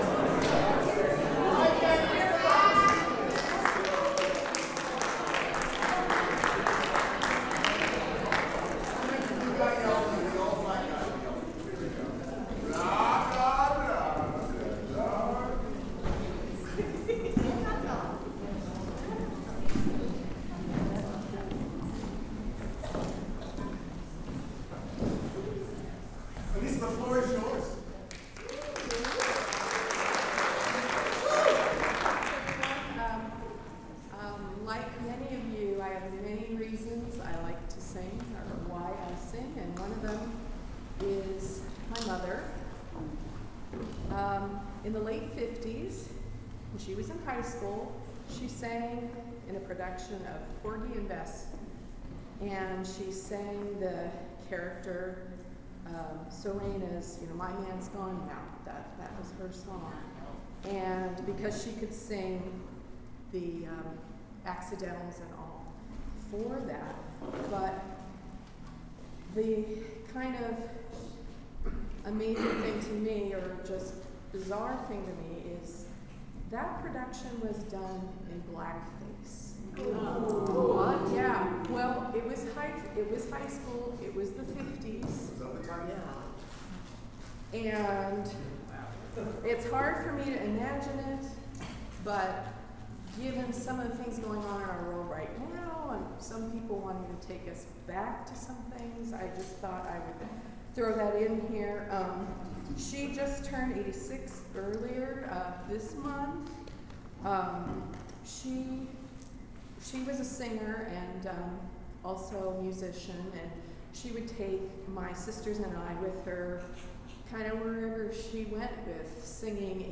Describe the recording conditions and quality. Start Alto sectional, starting with a warmup